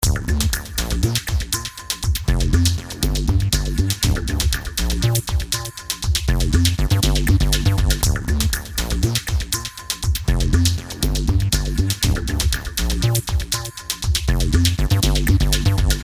描述：高能量的电子融合套装。
标签： 120 bpm Electronic Loops Groove Loops 2.69 MB wav Key : Unknown
声道立体声